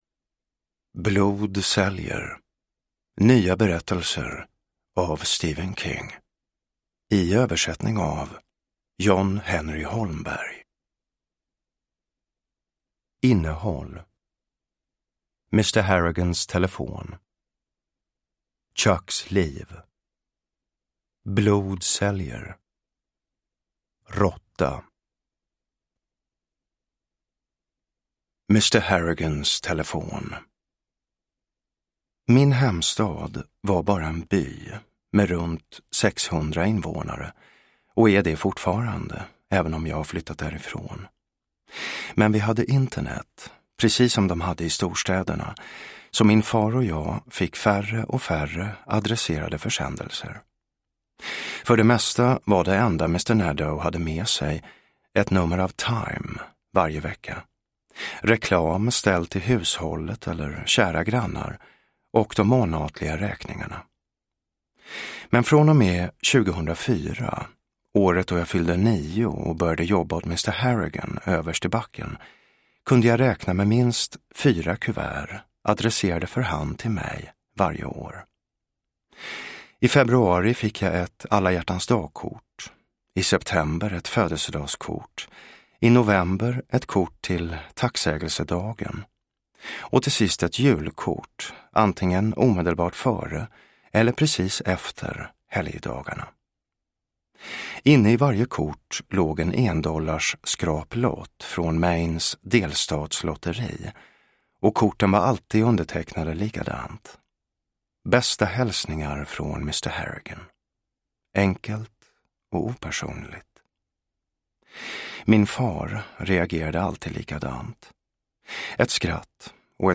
Blod säljer : nya berättelser – Ljudbok – Laddas ner
Uppläsare: Jonas Malmsjö, Jessica Liedberg